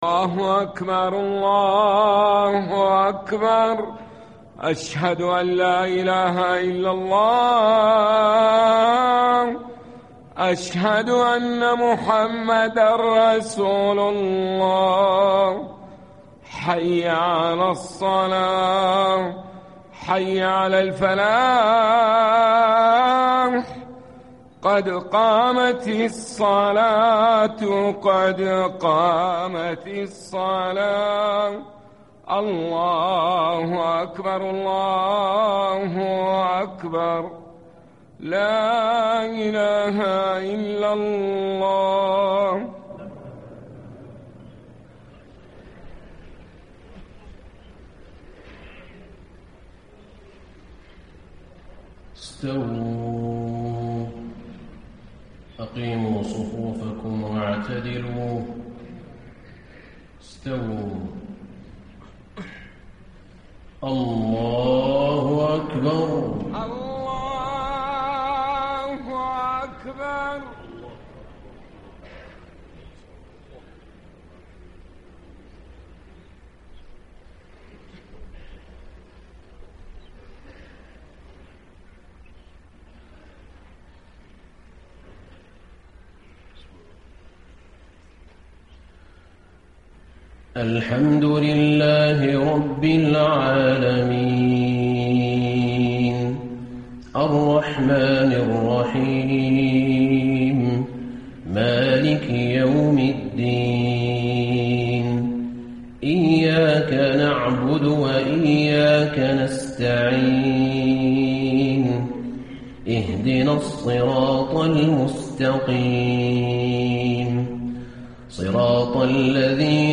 عشاء 11 شعبان ١٤٣٥ من سورة الانفطار و التكاثر > 1435 🕌 > الفروض - تلاوات الحرمين